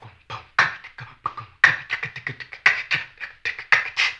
HAMBONE 16-R.wav